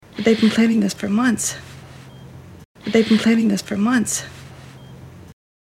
수술을 앞둔 환자와 의사가 나누는 대화입니다.